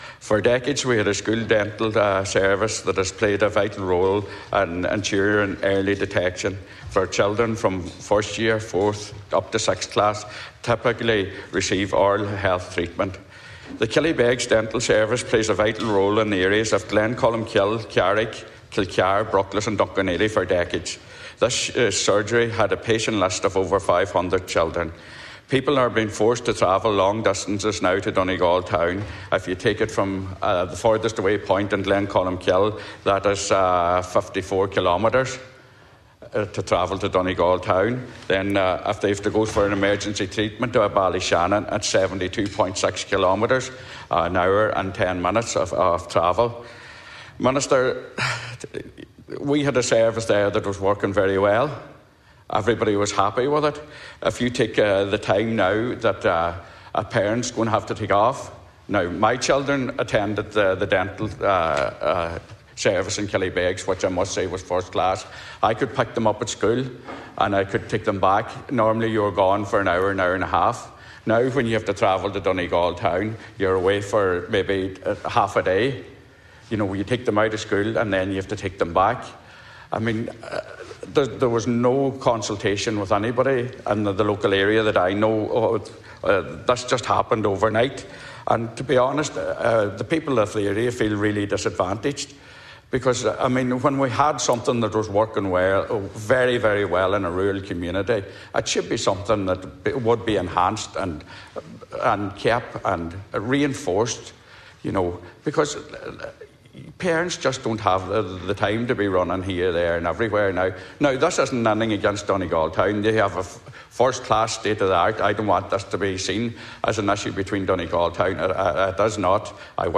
Senator Manus Boyle has been speaking in the Seanad today regarding the closure of the dental practice in Killybegs last December.
In response, Junior Health Minister, Mary Butler said she will highlight his concerns: